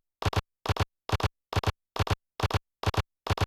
LOFI CLAP -L.wav